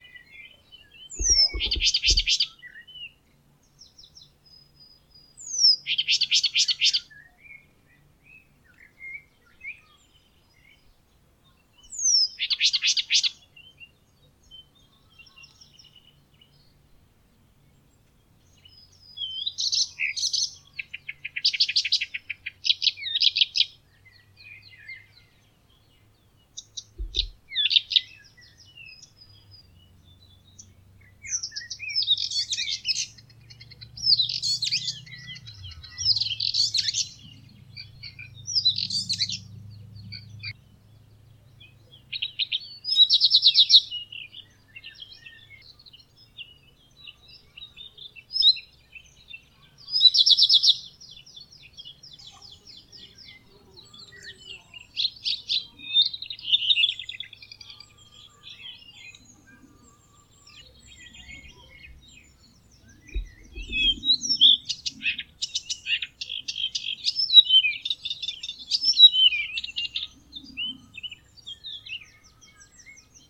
struikrietzanger
🔭 Wetenschappelijk: Acrocephalus dumetorum
♫ zang
struikrietzanger_zang.mp3